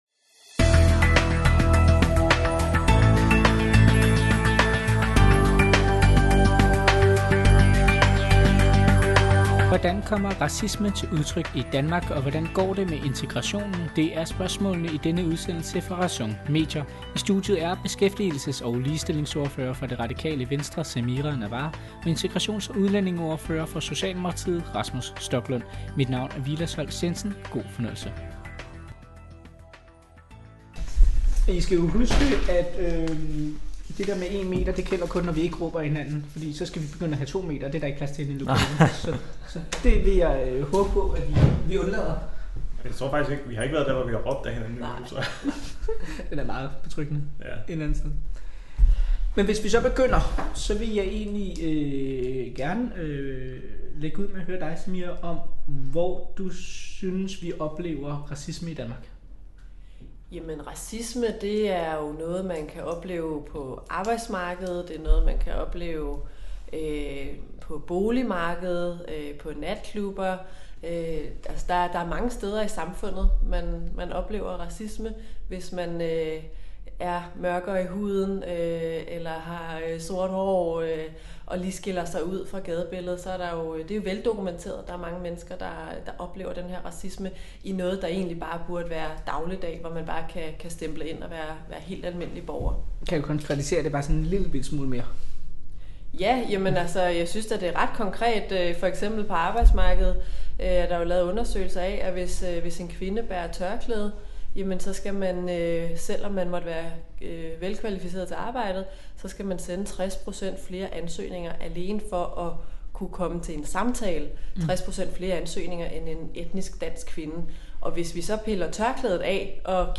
Hvordan oplever vi racisme i Danmark? Og i hvilket omfang har det konsekvenser for integrationen? Det debatterer Rasmus Stoklund, Integrations- og udlændingeordfører for Socialdemokratiet, og Samira Nawa, Beskæftigelses- og ligestillingsordfører for det Radikale Venstre, i en ny podcast fra RÆSON.